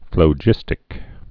(flō-jĭstĭk)